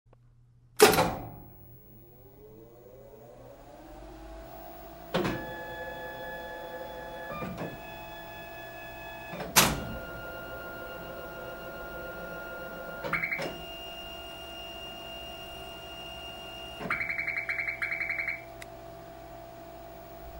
まず、不調時の動作音をお聴きください。再生した瞬間大きな音が出ます。
電源を投入するとファンの音が高まっていき安定します。5秒程経つと原点検出の動作に入ります。しかし10秒辺り、文字枠の固定と同時に機械原点から座標の最大値（点示板の右下）へと印字位置が勝手に走り出し、これ以上移動できない位置に到達すると「ピコピコピコピコ……」というエラー音とともに停止します。